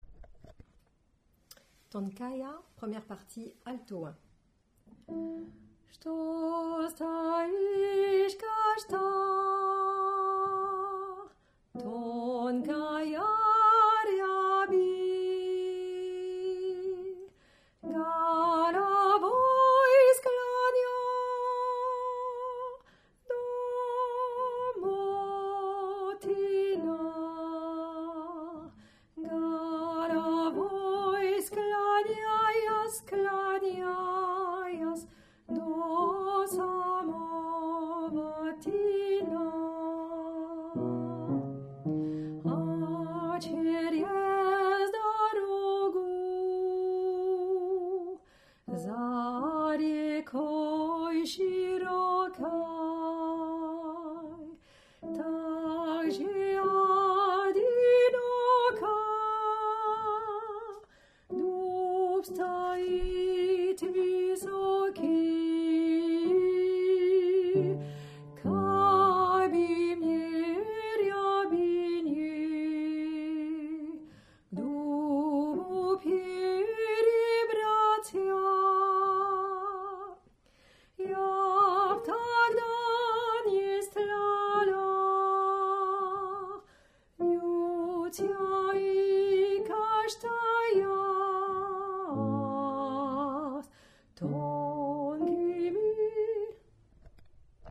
Alto1
tonkaia1_Alto1.mp3